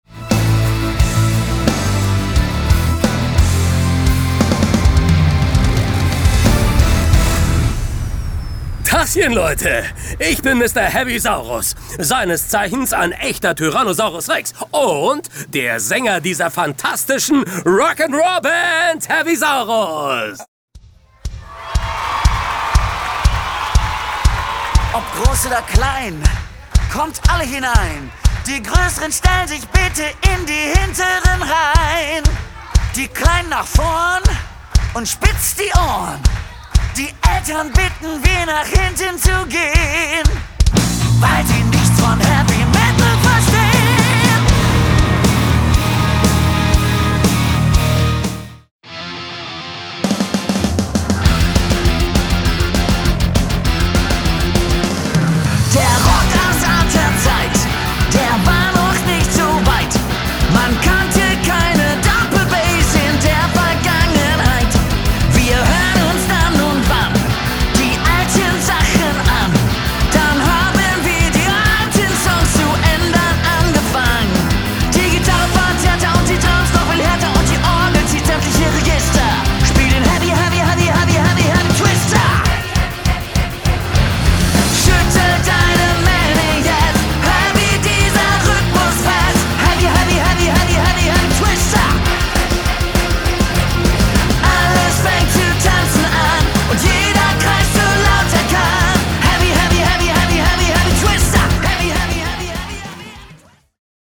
Keyboard
Gitarre
Drums
Bass